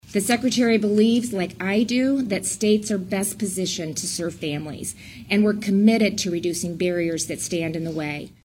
MCMAHON AND GOVERNOR REYNOLDS MADE THEIR COMMENTS AFTER TOURING AN ELEMENTARY SCHOOL IN DENISON AND VISITING WITH STUDENTS.